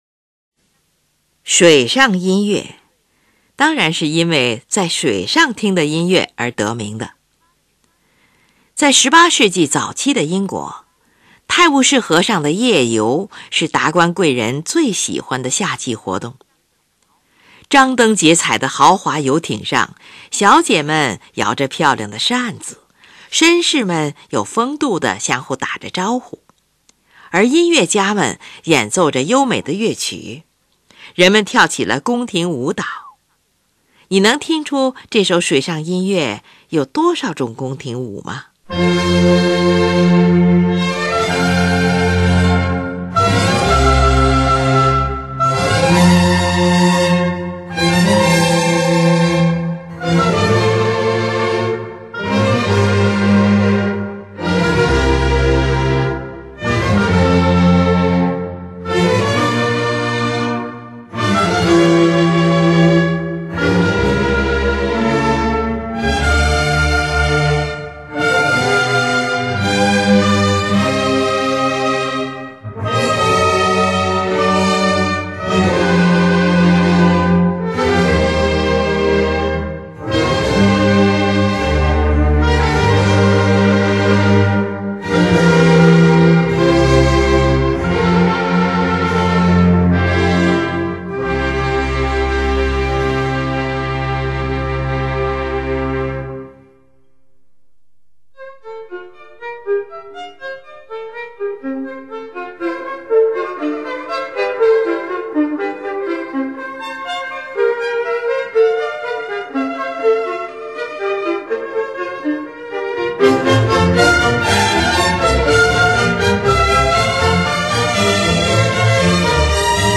是一部管弦乐组曲
乐器使用了小提琴、低音提琴、日耳曼横笛、法兰西横笛、双簧管、圆号、小号等